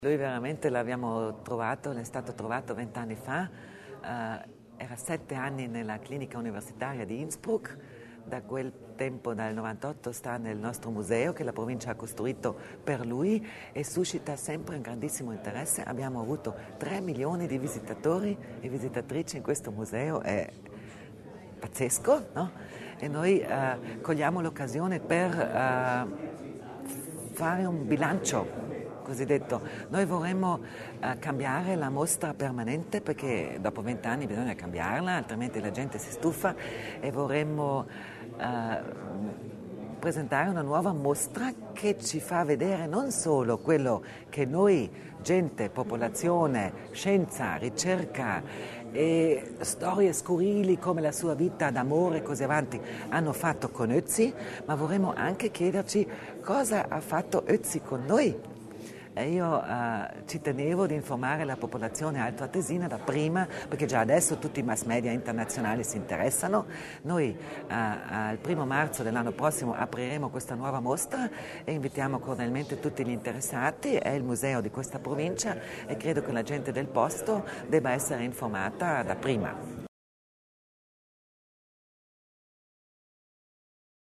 L’Assessore Kasslatter Mur sull’importante ricorrenza
L’esposizione è stata presentata questa mattina (5 novembre) dall’assessora provinciale alla cultura tedesca, Sabina Kasslatter Mur, nel corso di una conferenza stampa a Palazzo Widmann.